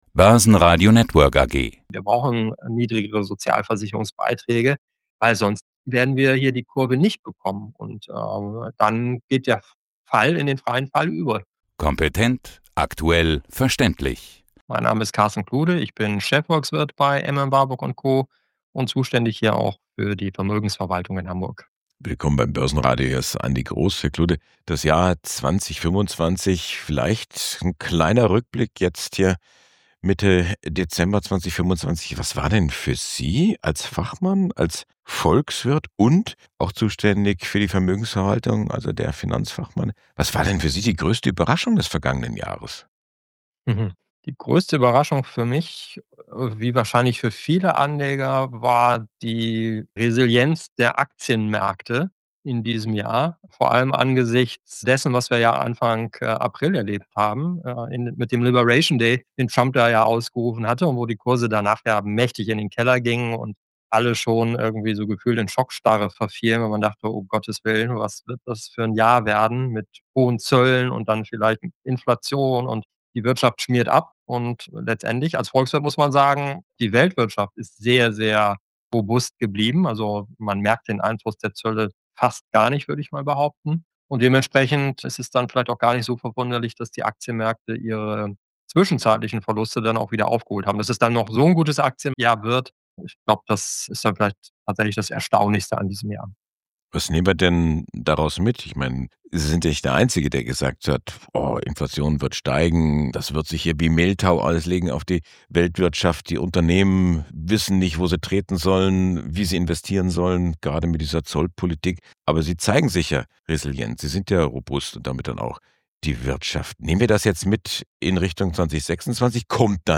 Im Gespräch mit dem Börsen Radio